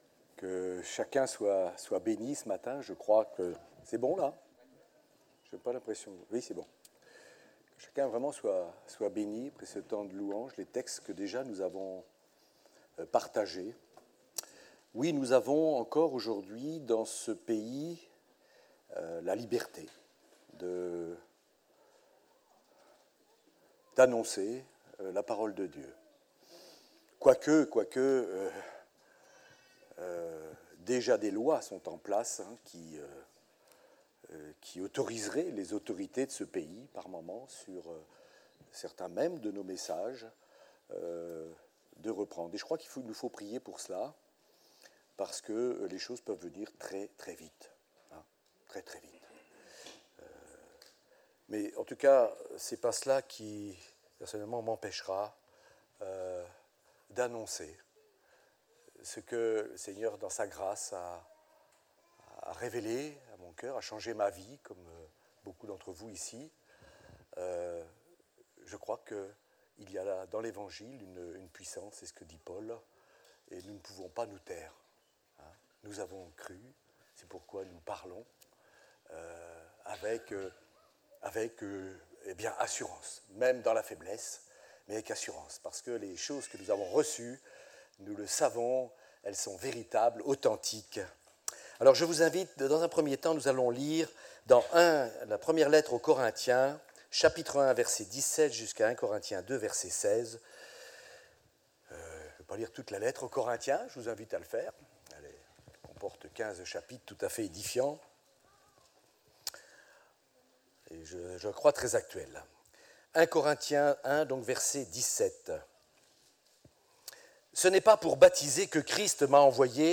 Messages audio | La Bonne Nouvelle